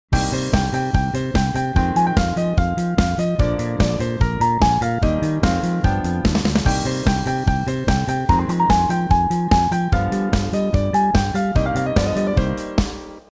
意外にもマイナーコードが多い